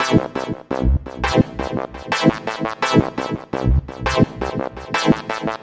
标签： 女声 电子 配音 dubstep 吉他 贝斯 合成器 循环 寒冷 环境
声道立体声